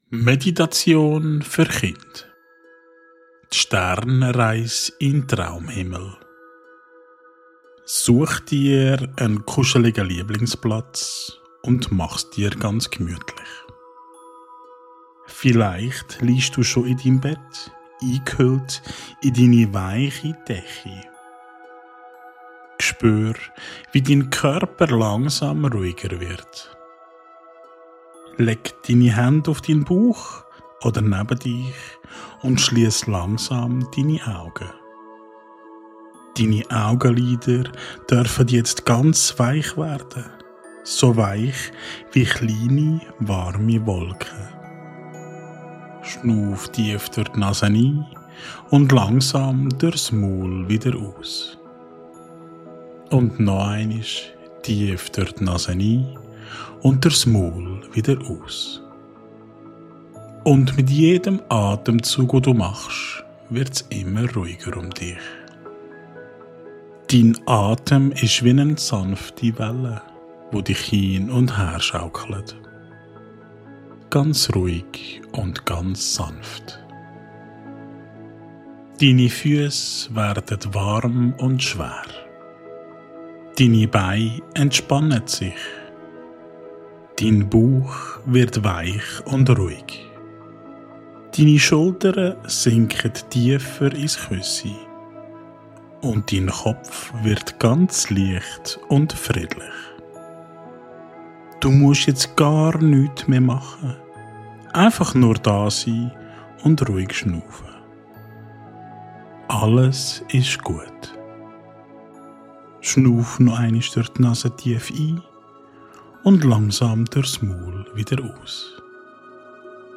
Beschreibung vor 2 Tagen Diese geführte Meditation ist für Kinder gemacht, die sanft zur Ruhe kommen, entspannen und sich geborgen in ihren Schlaf begleiten lassen möchten. In dieser liebevollen Fantasiereise begeben sie sich auf eine magische Sternreise – hoch hinauf in den funkelnden Nachthimmel.